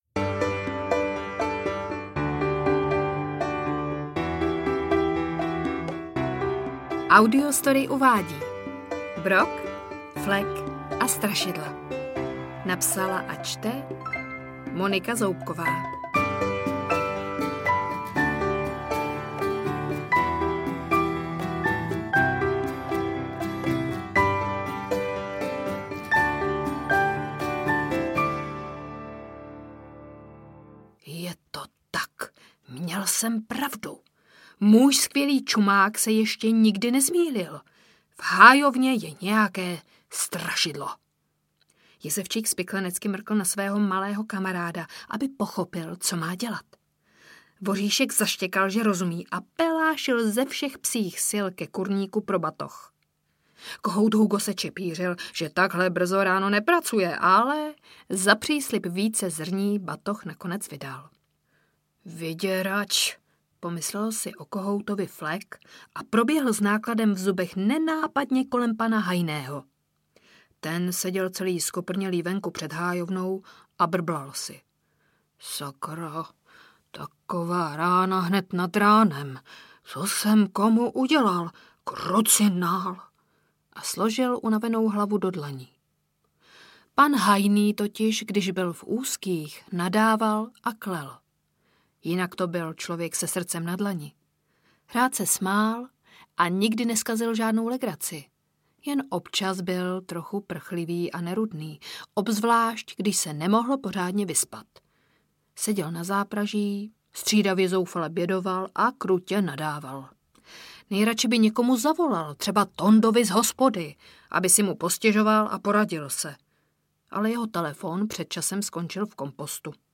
Brok, Flek a strašidla audiokniha
Ukázka z knihy